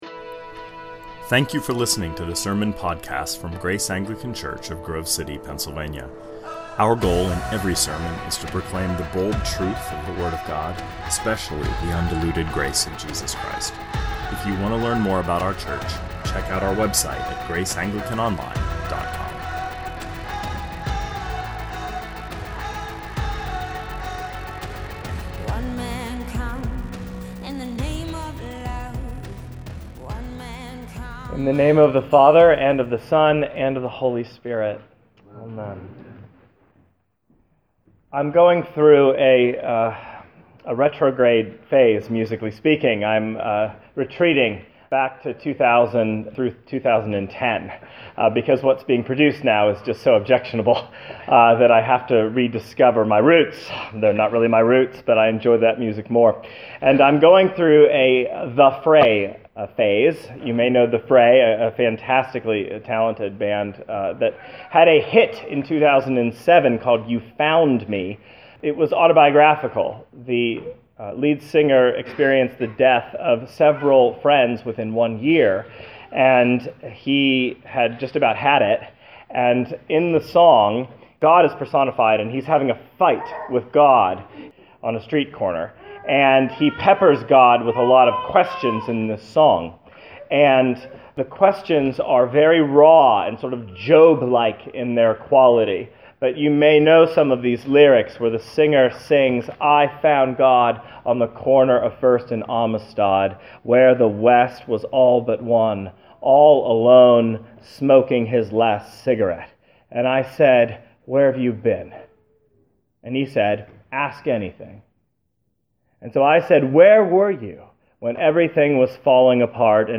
2019 Sermons On the Corner of First and Amistad -Luke 13 Play Episode Pause Episode Mute/Unmute Episode Rewind 10 Seconds 1x Fast Forward 30 seconds 00:00 / 25:18 Subscribe Share RSS Feed Share Link Embed